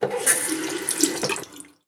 CosmicRageSounds/sink.ogg_1909ef67 at 4f151c074f69b27e5ec5f93e28675c0d1e9f0a66